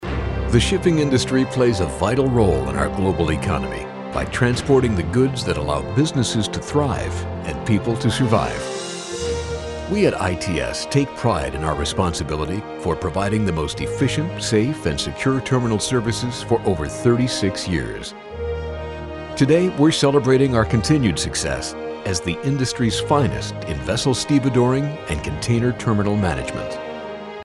ISDN equipped home studio.
Authoritative, Confident, Professional, Warm
Sprechprobe: Industrie (Muttersprache):